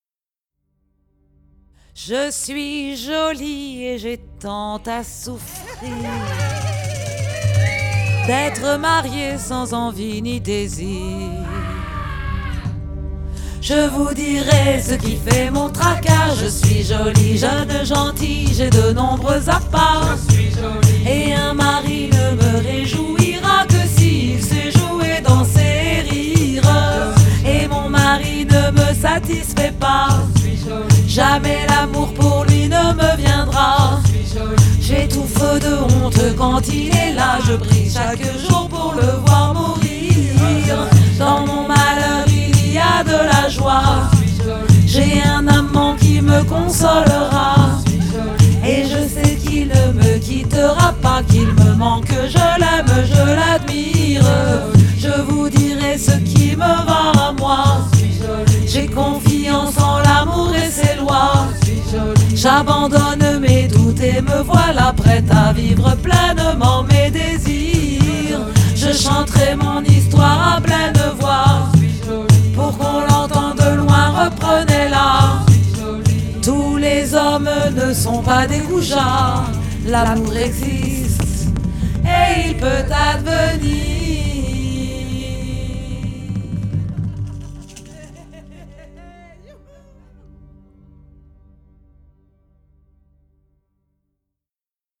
Quelques histoires de femmes au XIIème Siècle Contes, Chansons, Lais, Fables, Cansos, Tensos, Sirventès et musiques Aliénor d’Aquitaine est ...
voix